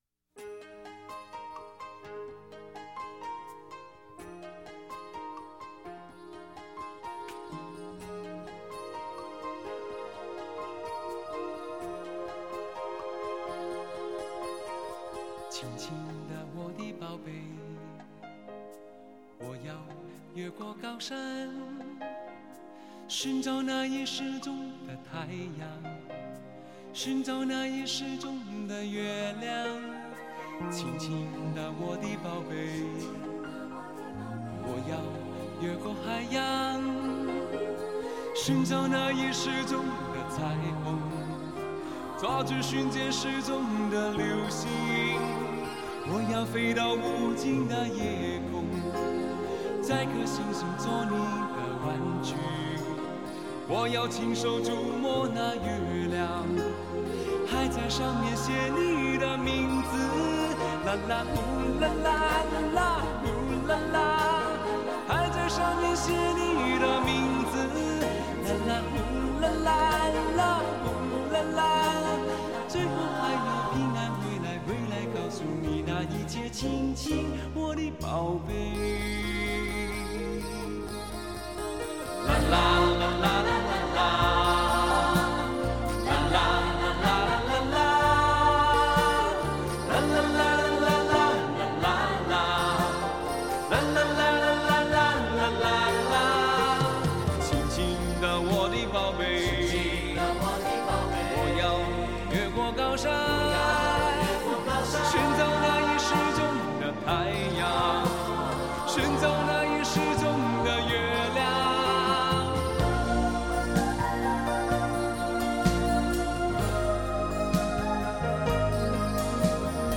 嘹亮悦耳的高音
宽厚深沉的低音